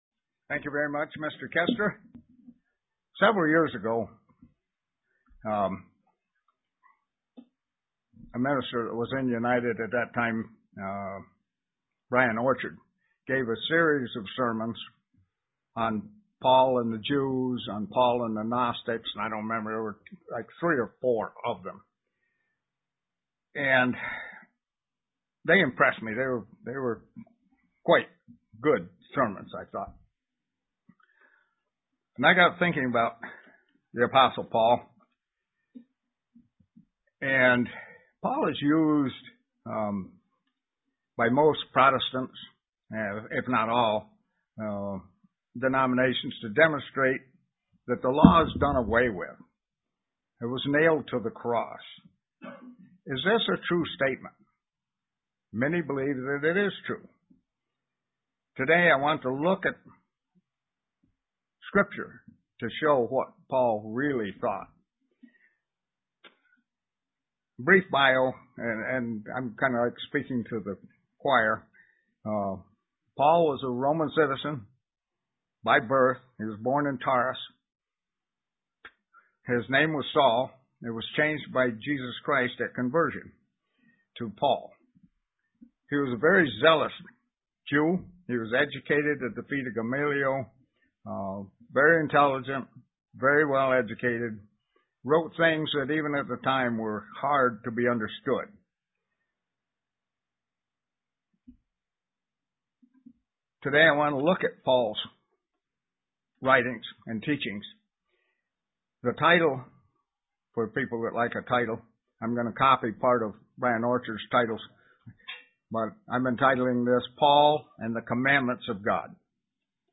Print Paul's teaching of the Ten Commandments UCG Sermon Studying the bible?
Given in Elmira, NY